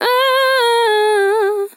TEN VOCAL FILL 26 Sample
Categories: Vocals Tags: dry, english, female, fill, sample, TEN VOCAL FILL, Tension